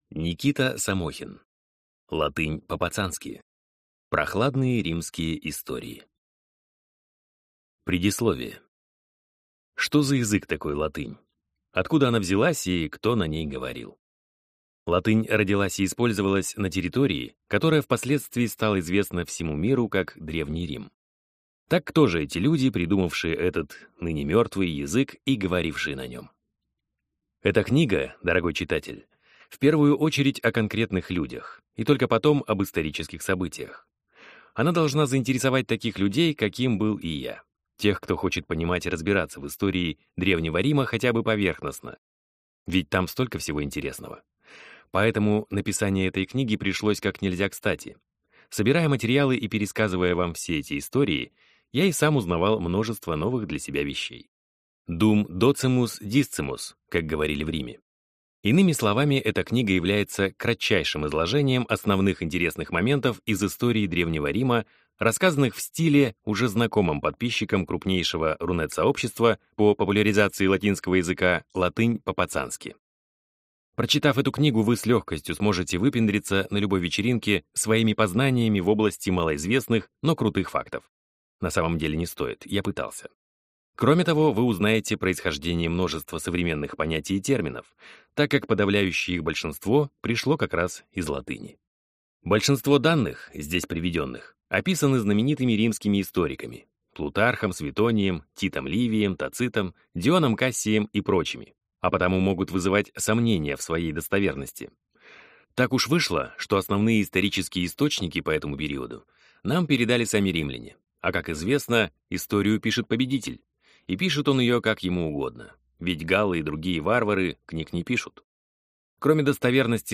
Аудиокнига Латынь по-пацански. Прохладные римские истории | Библиотека аудиокниг